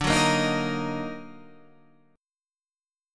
Ebm/D chord